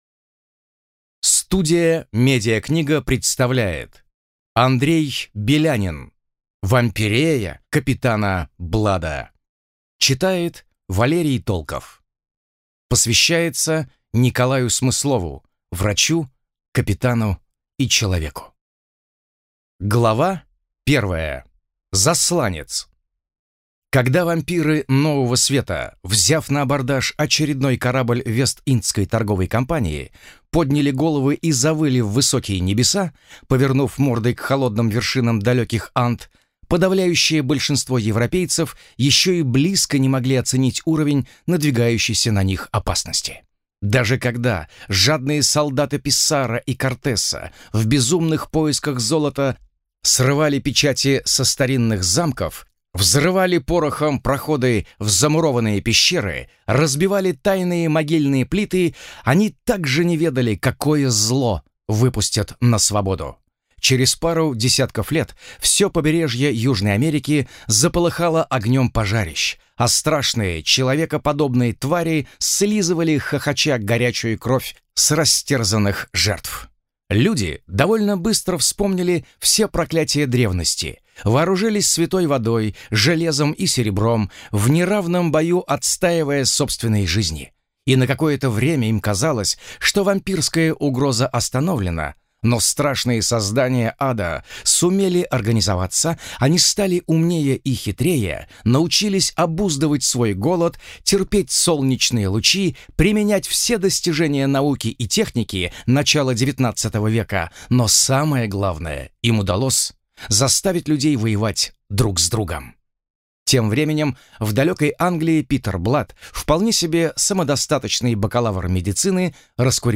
Аудиокнига Вампирея капитана Блада | Библиотека аудиокниг